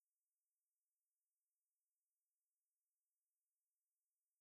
It seems to create a little radio station only instead of broadcasting a powerful signal from a tall tower, it does it with an ultra-sonic tone (dogs and bats can hear it).